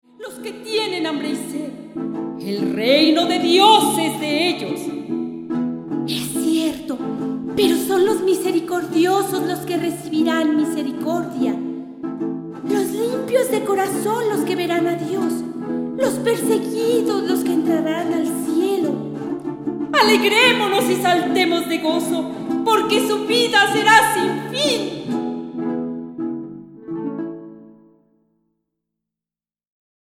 soprano, flauta, percusiones
violín, violín piccolo
guitarra barroca, laúd, mandolina, charango, viola de gamba